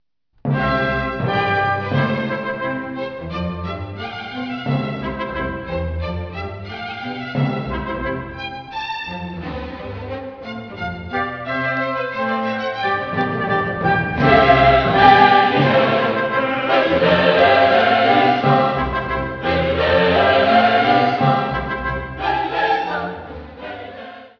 Osterhochamt am 23. April um 10:00 Uhr in St. Bonifatius, Großwelzheim.
Die Besetzung mit Pauken und Trompeten unterstreicht den festlichen Charakter der Musik.